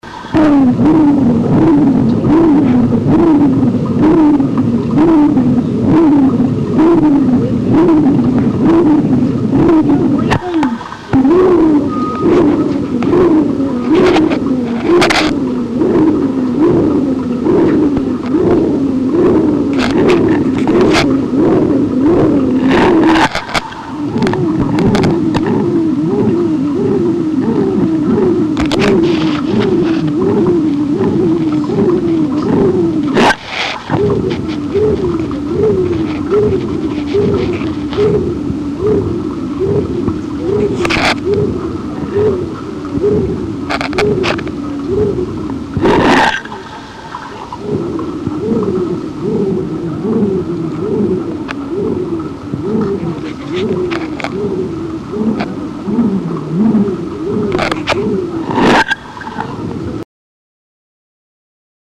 Shuntgeluiden
Ruis- en fluittoon
Nummer-5-Ruis-en-fluittoon.mp3